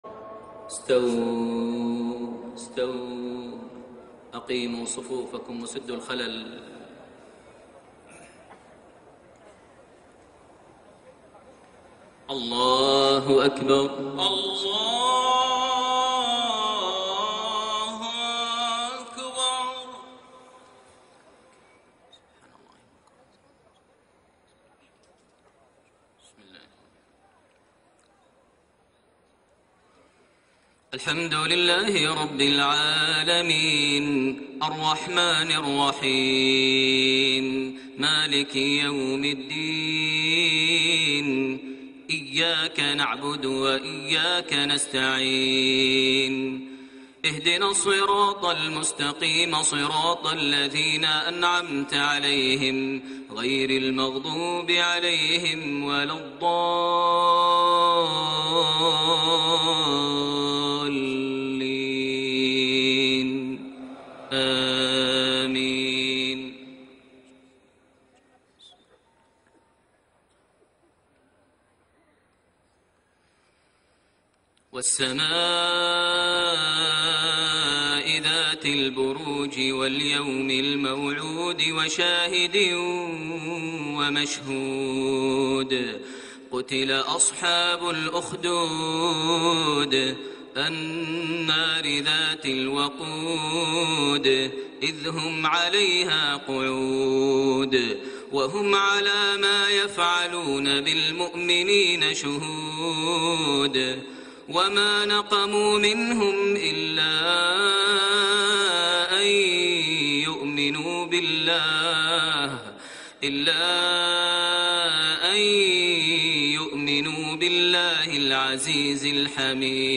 صلاة المغرب 7 ربيع الثاني 1433هـ سورة البروج > 1433 هـ > الفروض - تلاوات ماهر المعيقلي